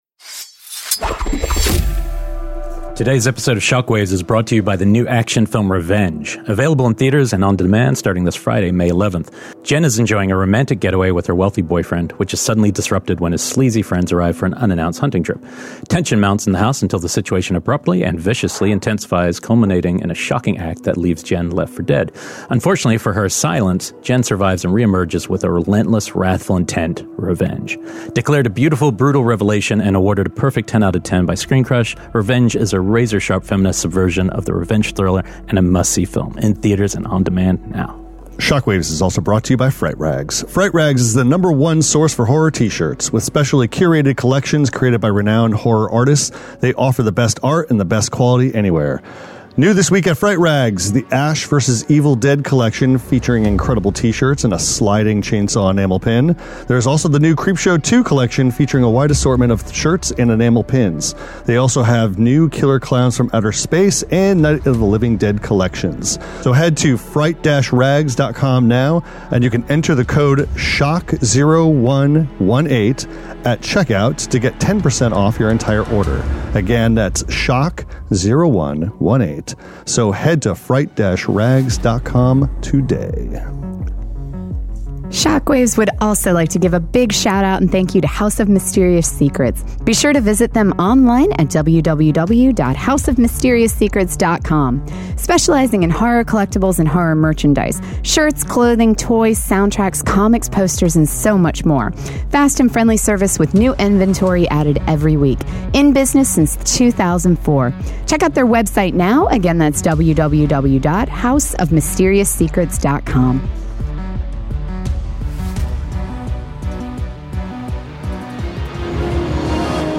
Kick back, relax, and join the conversation!